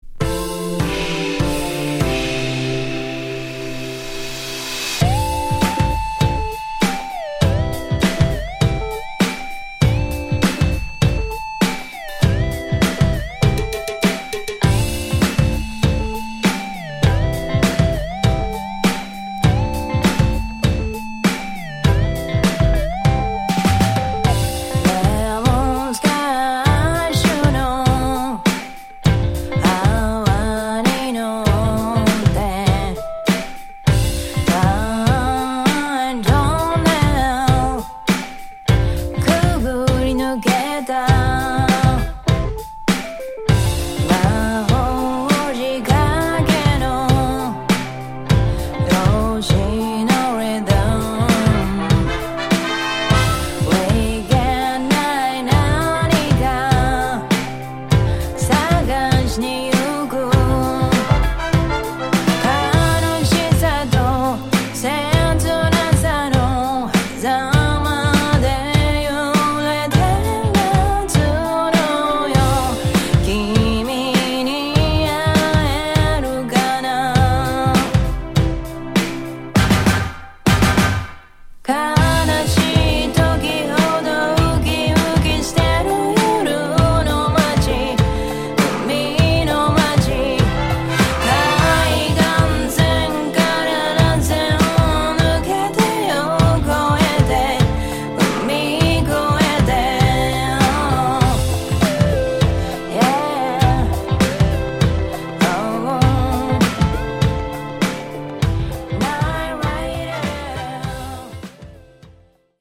ジャンル(スタイル) JAPANESE POP / CITY POP